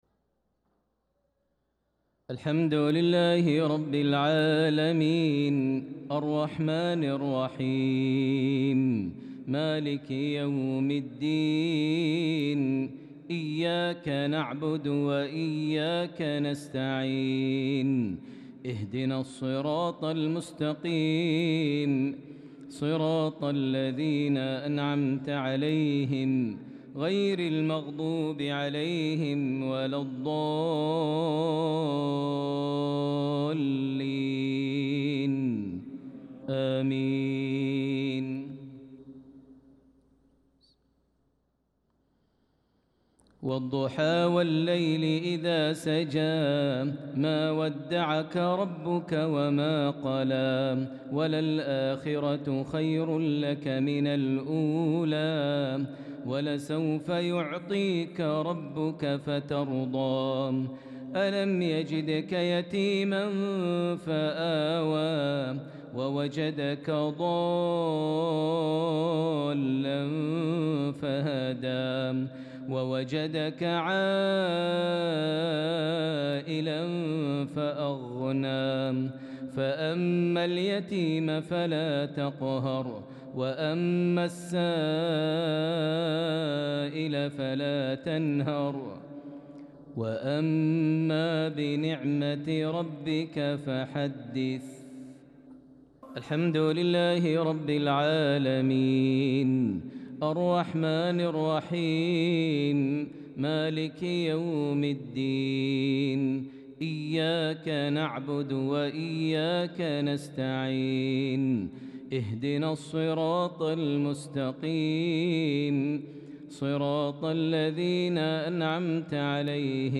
صلاة المغرب للقارئ ماهر المعيقلي 26 ذو الحجة 1445 هـ
تِلَاوَات الْحَرَمَيْن .